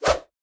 fire1.ogg